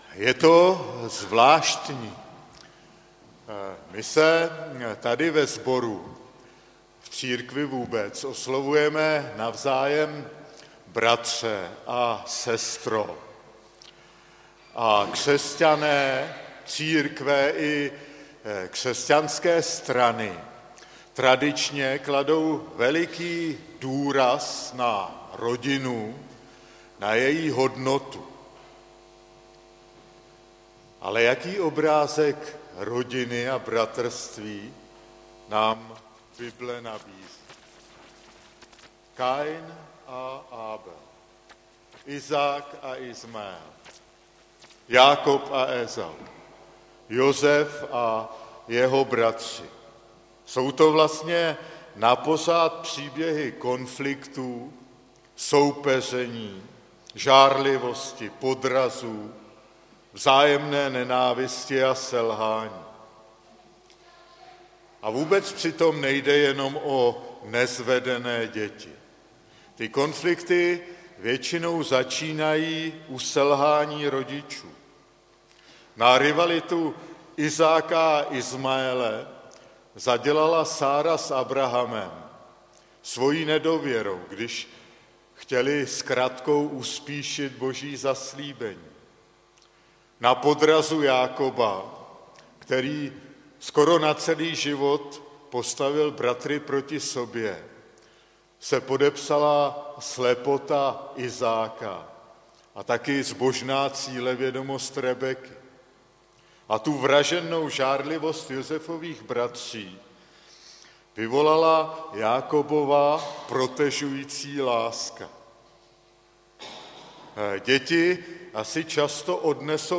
Kázáníčko - rodinné bohoslužby 17.1.2016
kázání jako mp3.